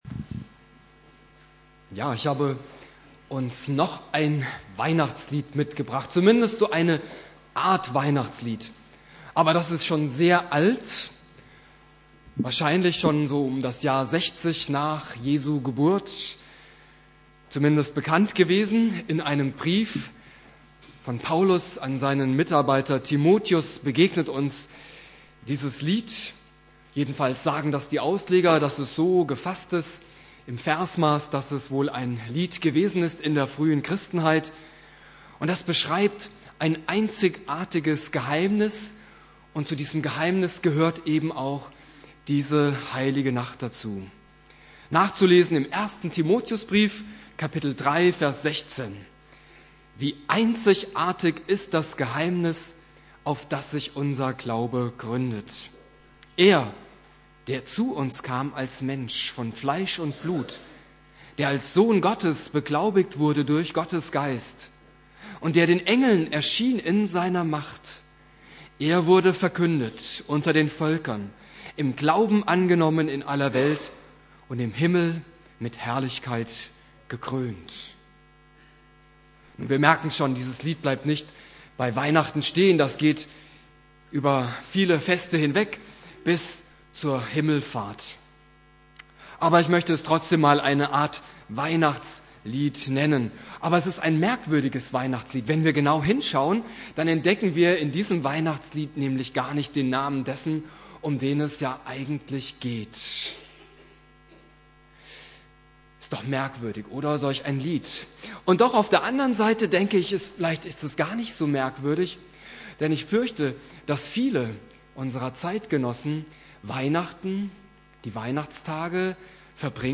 Heiligabend Prediger